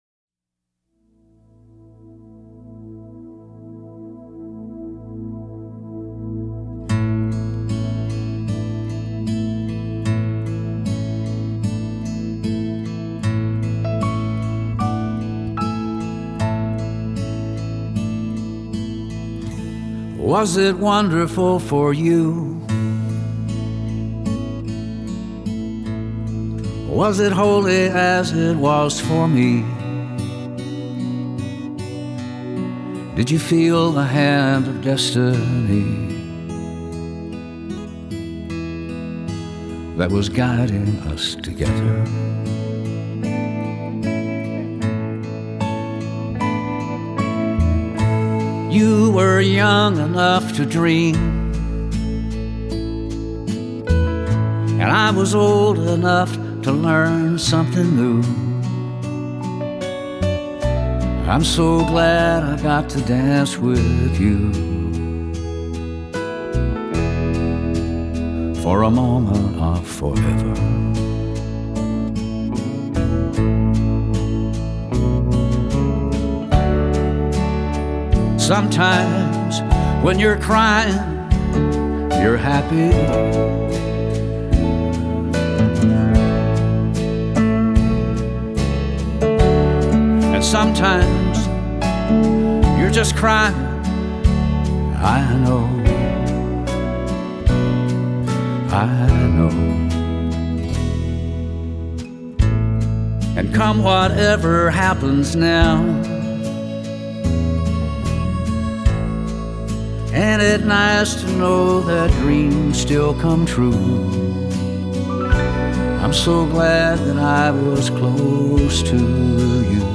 专辑流派：Country